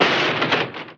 hitrock.mp3